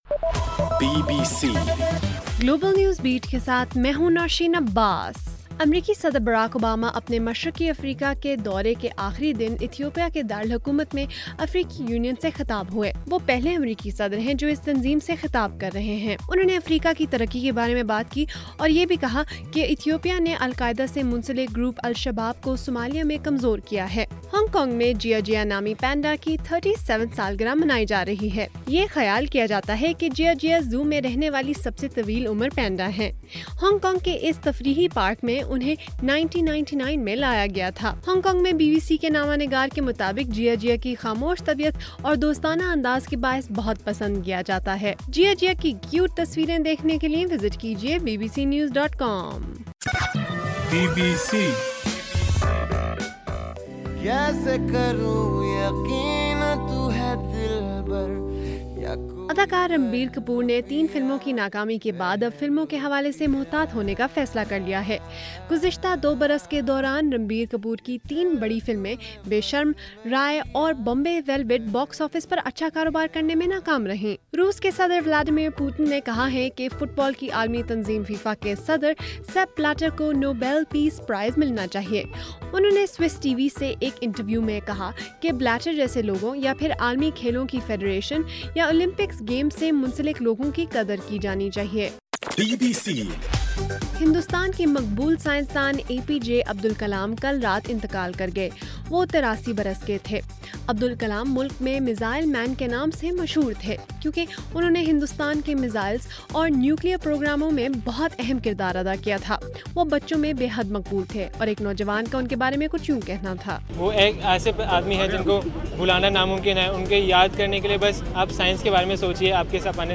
جولائی 28: رات 10 بجے کا گلوبل نیوز بیٹ بُلیٹن